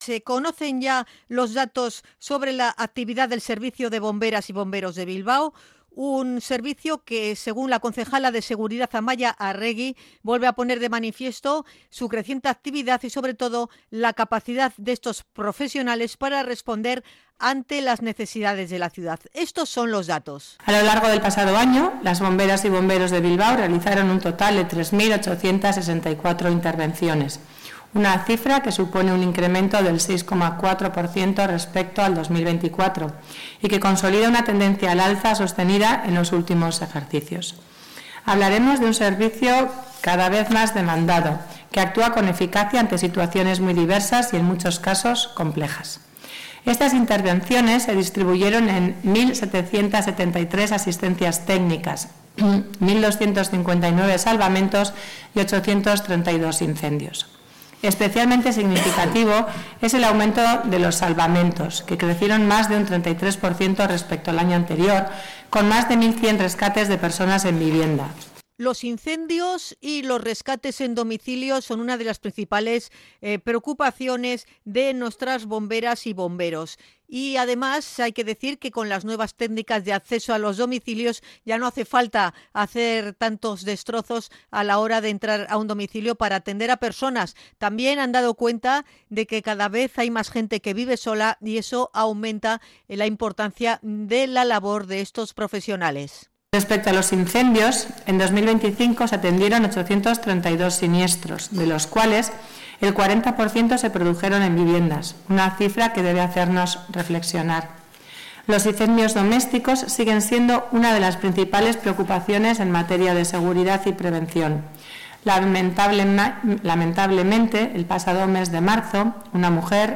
CRONICA-MAY-BOMBEROS.mp3